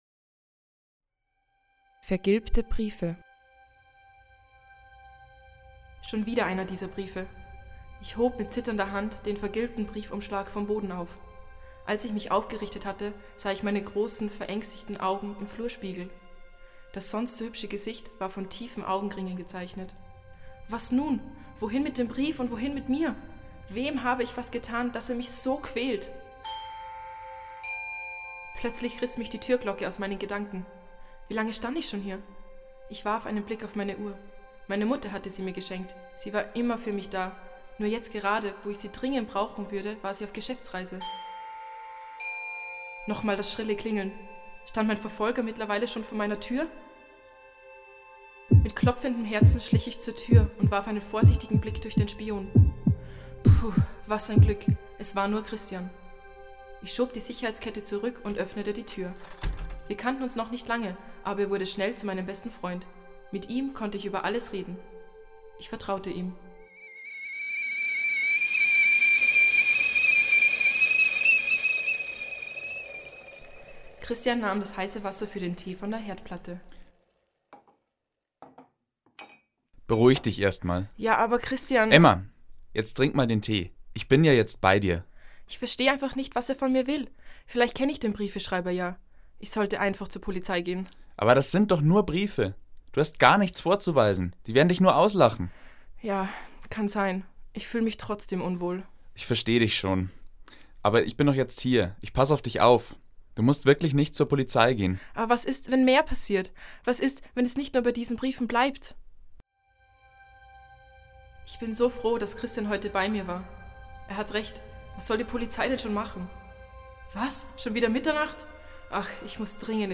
24. Hörspiel
Hoerspiel_VergilbteBriefe_Teil1.mp3